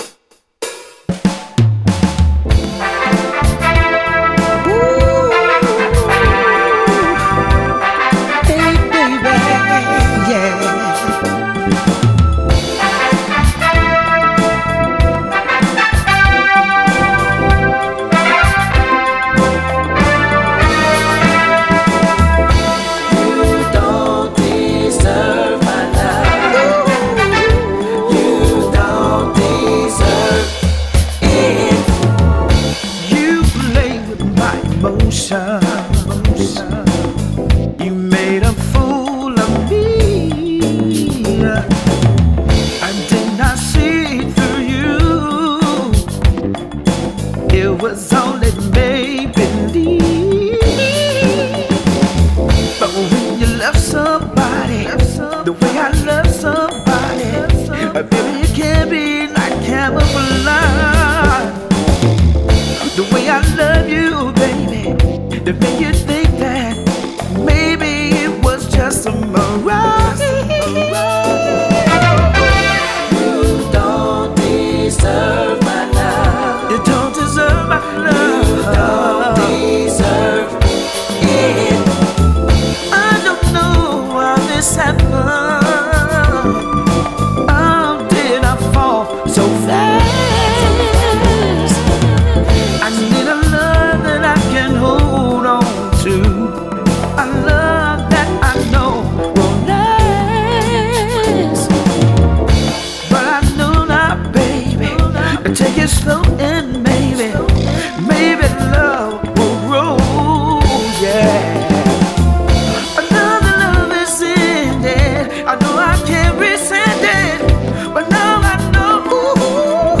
Click the link below to hear the interview conducted on July 2nd 2018